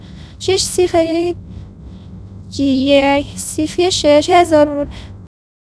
persian-tts-female-GPTInformal-Persian-vits like 0